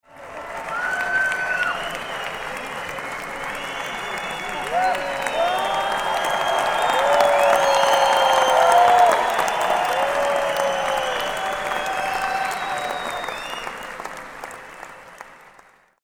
Live-rock-concert-crowd-reaction-sound-effect.mp3